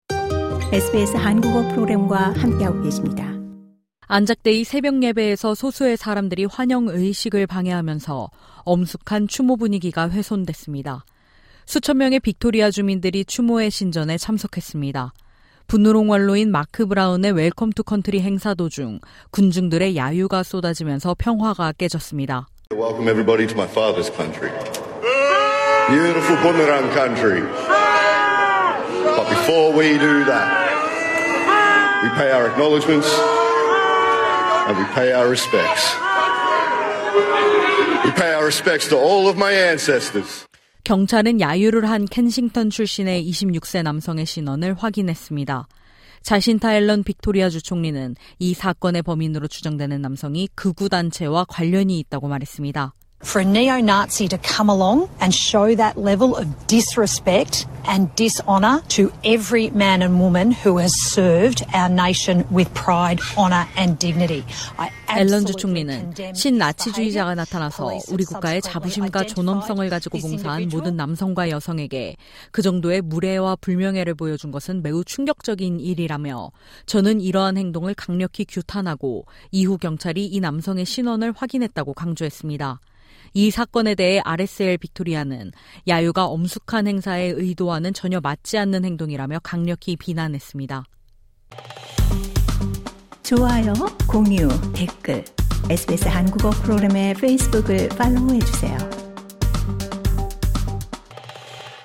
LISTEN TO 오디오 책갈피: '저주 토끼' 정보라 작가의 SF '너의 유토피아 Your Utopia' SBS Korean 07:26 Korean 상단의 오디오를 재생하시면 뉴스를 들으실 수 있습니다.